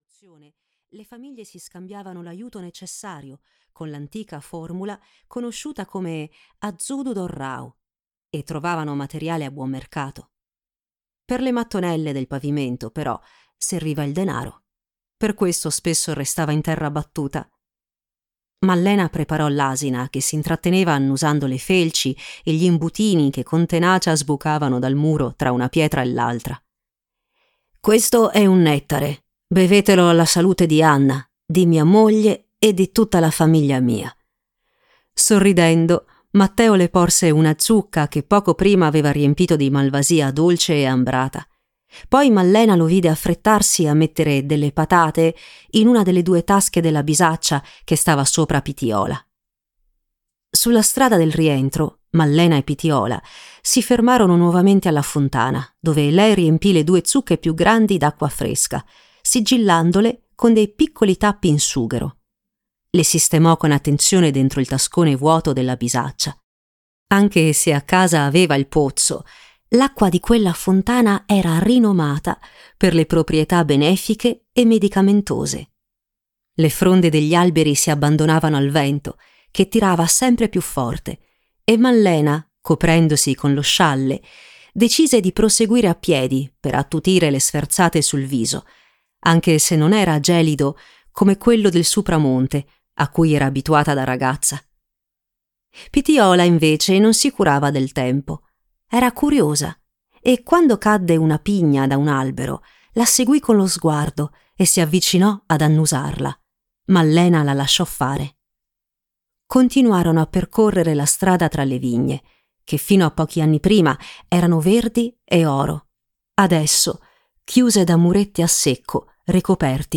"La levatrice" di Bibbiana Cau - Audiolibro digitale - AUDIOLIBRI LIQUIDI - Il Libraio